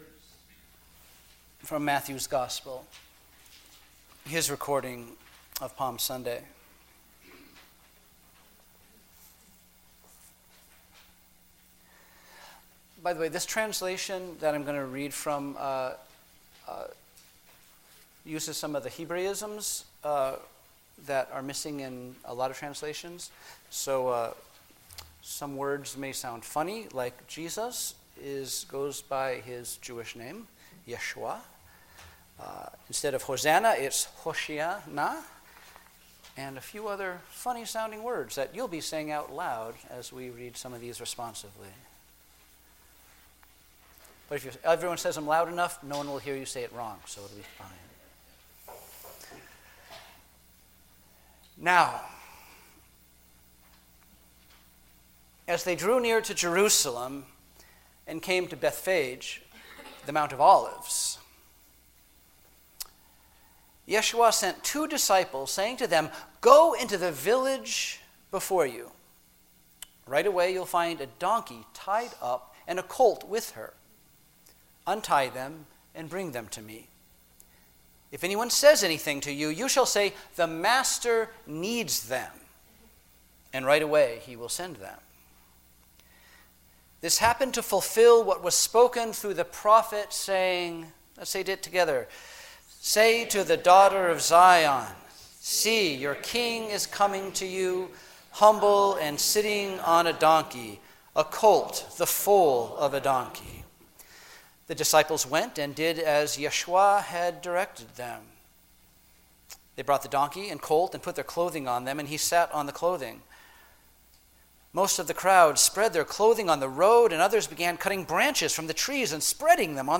Sermons Un-Tied for What?